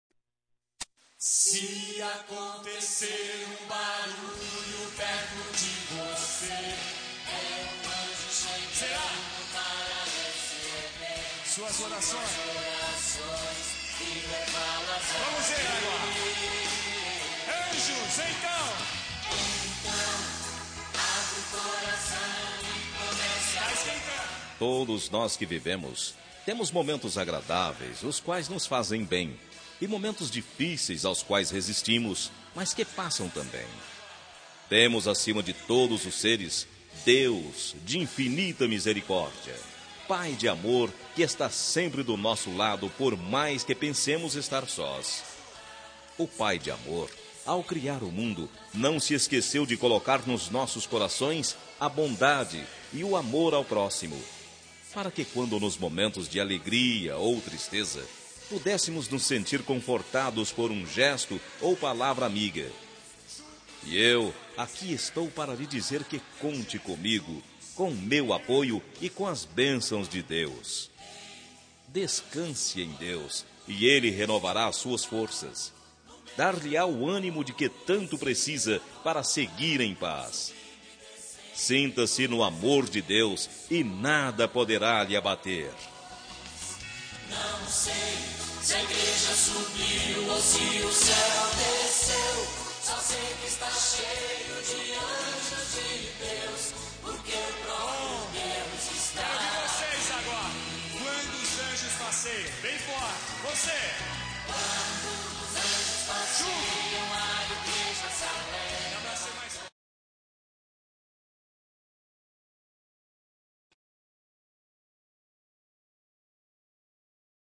Telemensagem de Otimismo – Voz Masculina – Cód: 4990-2 – Religiosa